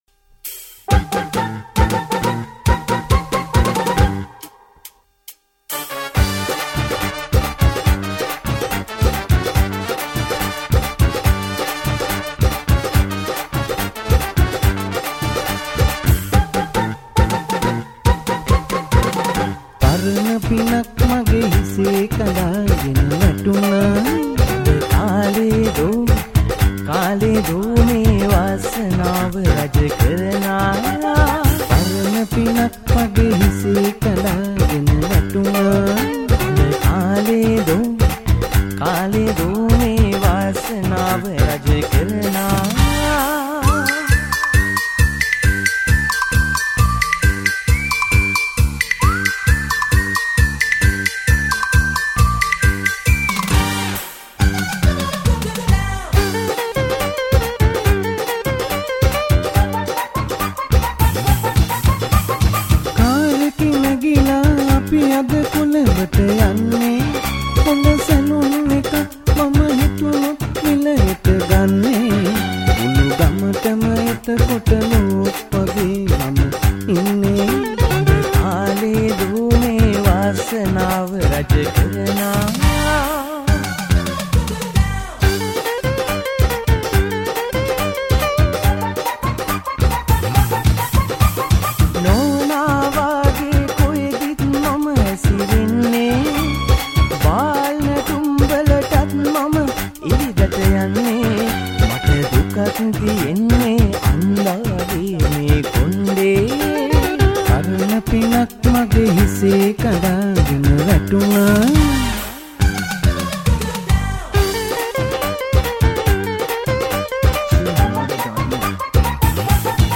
DJ Song